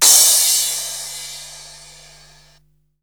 CRASHFX01 -L.wav